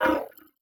Hi Tech Alert 6.wav